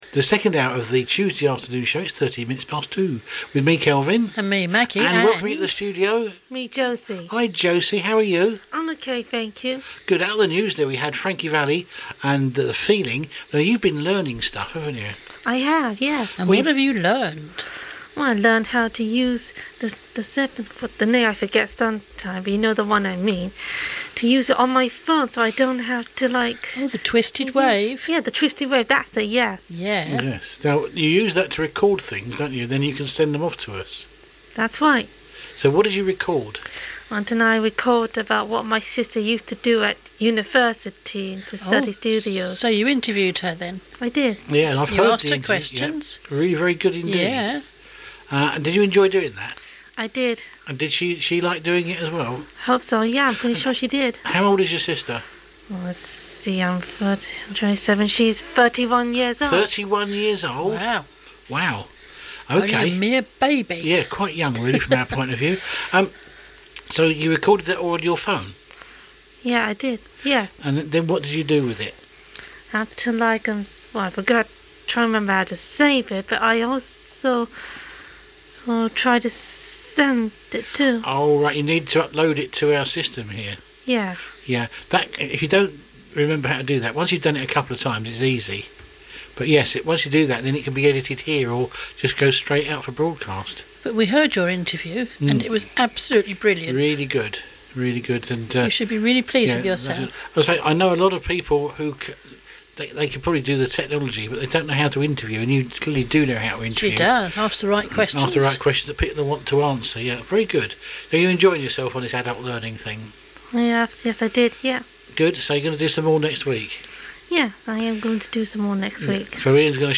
Session 4 Interview